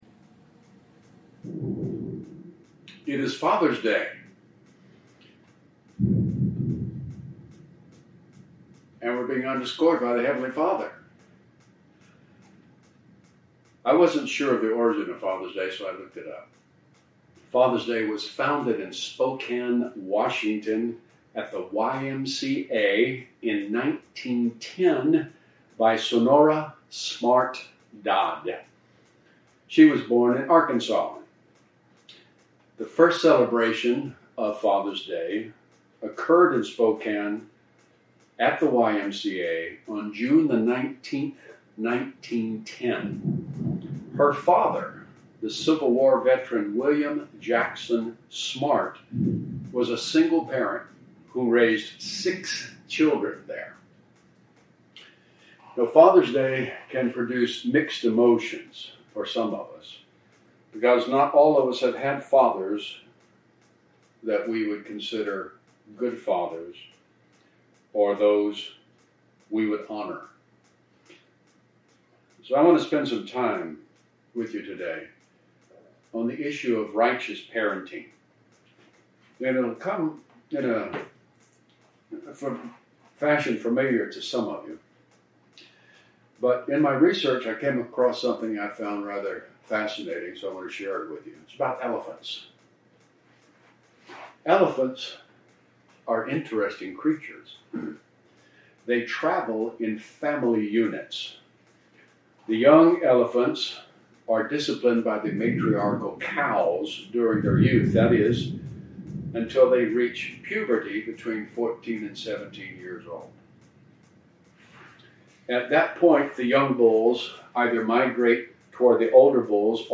Welcome to Our Father's House online service.